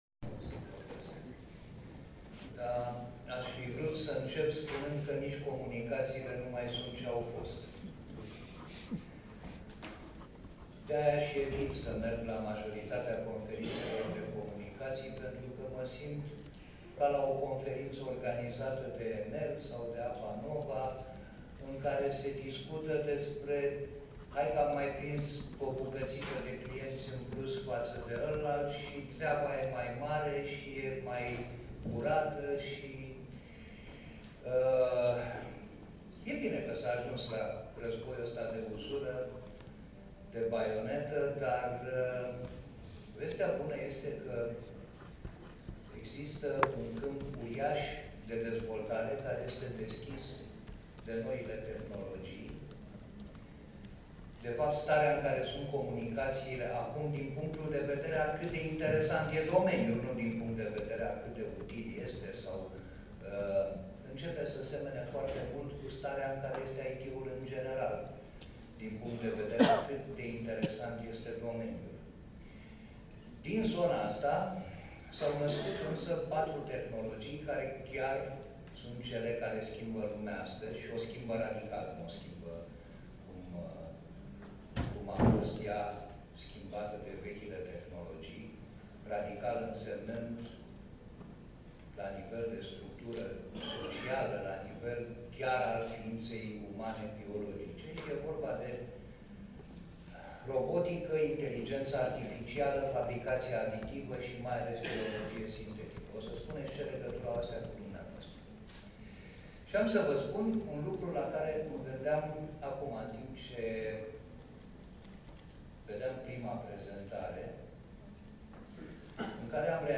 Forța de muncă umană va dispărea complet în 50 de ani în toate domeniile care presupun activități repetitive și bazate pe reguli”, a declarat marți Varujan Pambuccian, membru în Comisia IT din Camera Deputaților.
prezenți la evenimentul RONOG